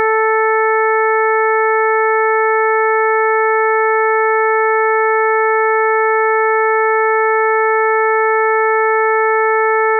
It contains a fundamental "A" note (440 Hz) plus four harmonics. The five tones fuse together in a perfect octave consonance, that is, a single perceived stream.
Figure 1a: Chord 440